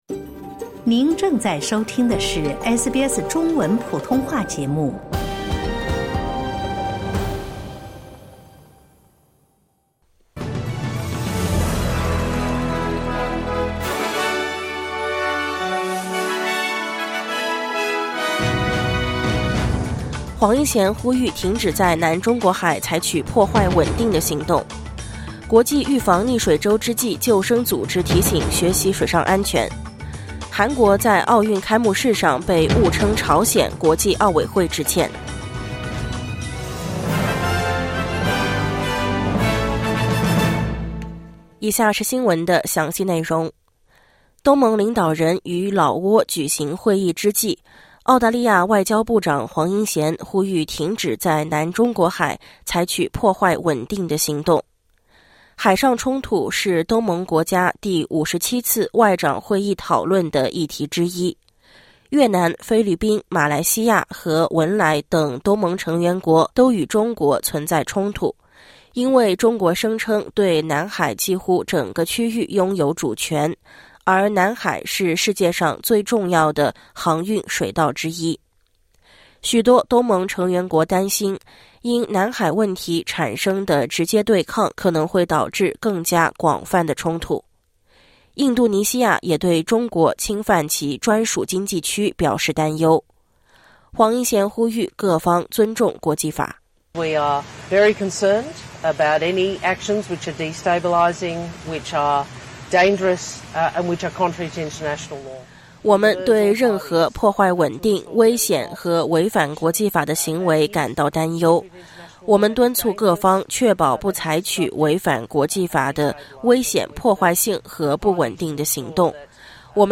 SBS早新闻（2024年7月28日）